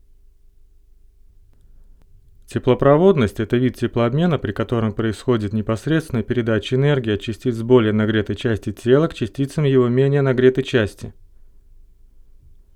Вот например запись днем при гейн 60 дБ,шумовая обстановка естественно плохая,но слышны больше не те шумы что -40,то есть шум комнаты,а именно то "ш" которые в -107 дБ...